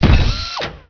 step.wav